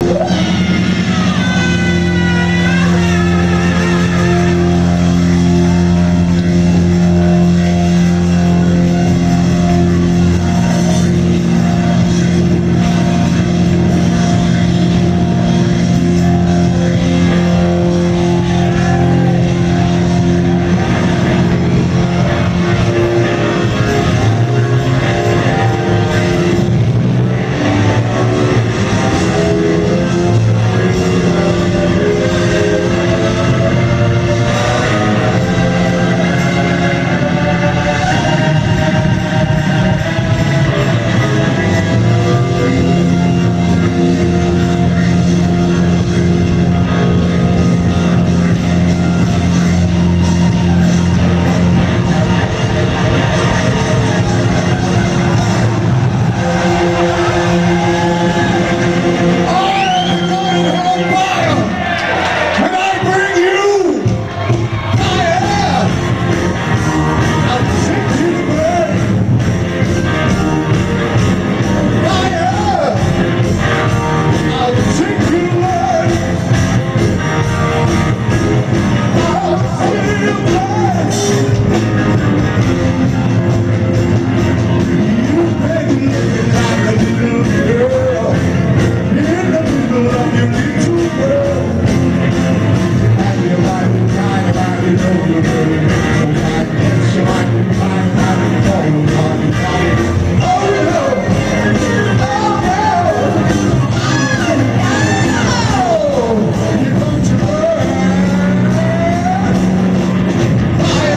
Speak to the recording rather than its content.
Febuary 2nd London Marquee